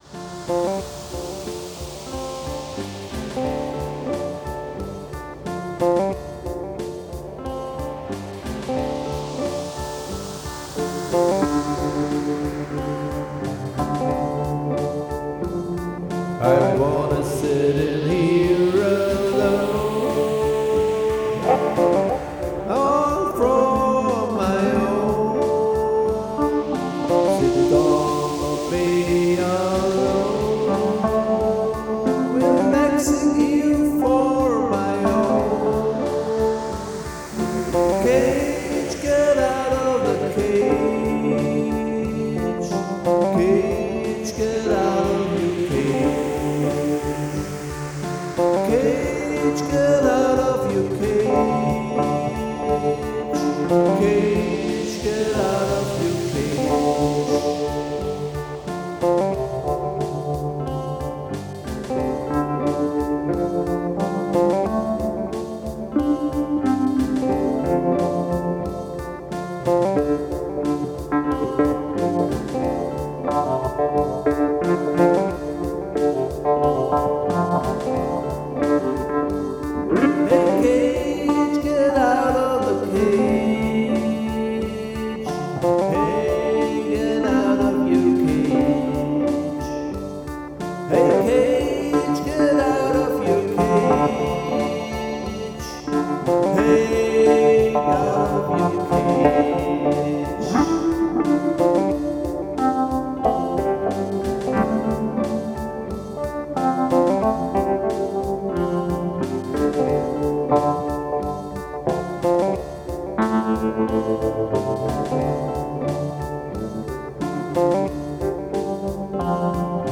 Melancholic amtosphere. Singersongwriter. Pulsating beats.